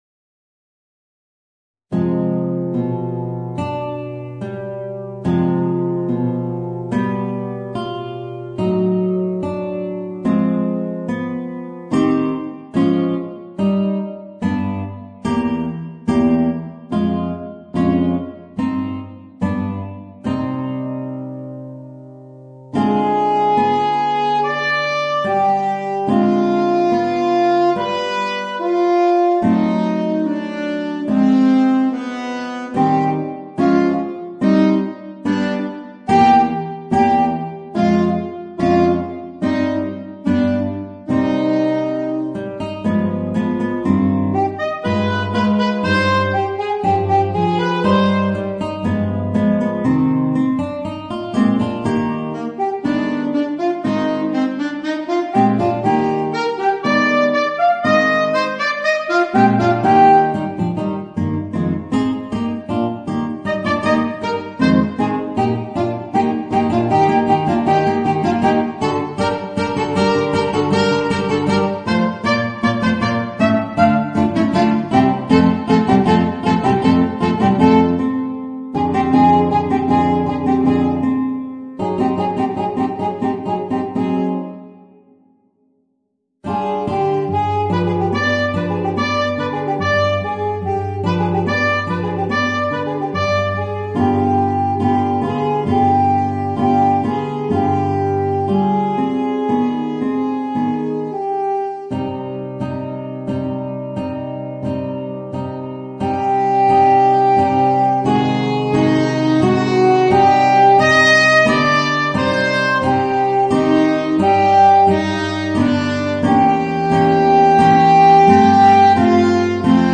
Voicing: Alto Saxophone and Guitar